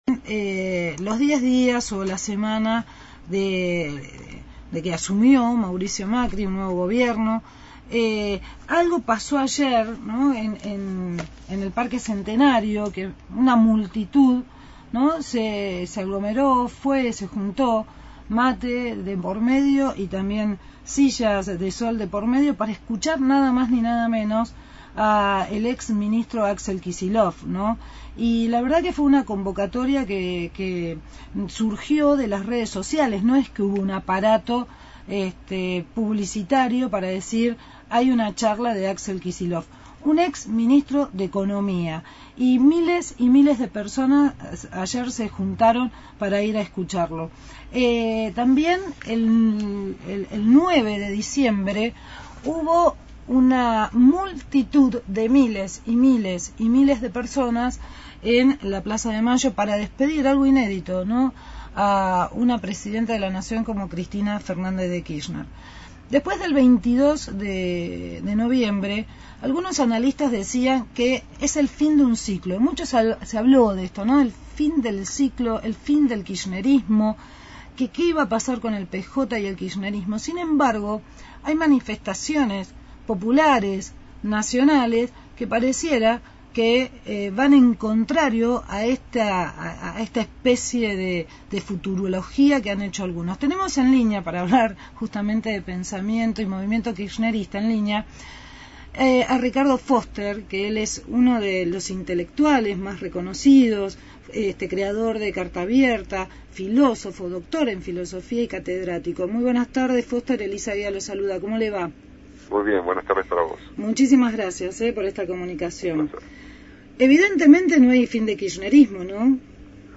Entrevista a Ricardo Forster, filósofo y ensayista argentino.